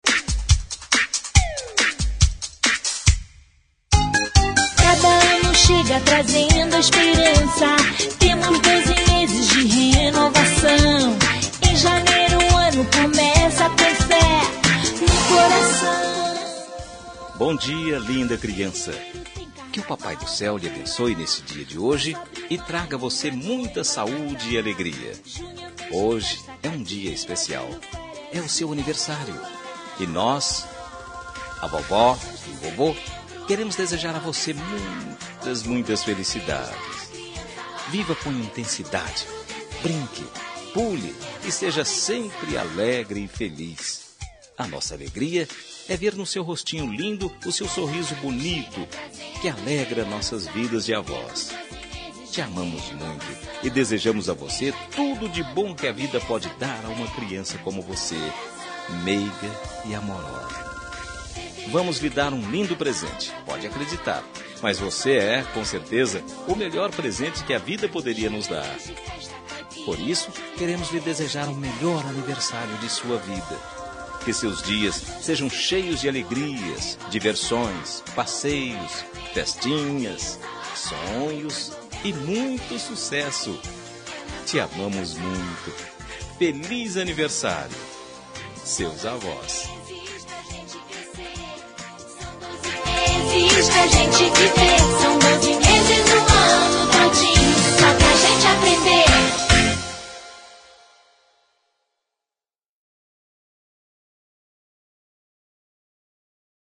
Aniversário de Neto – Voz Masculina – Cód: 131057